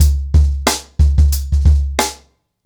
TrackBack-90BPM.45.wav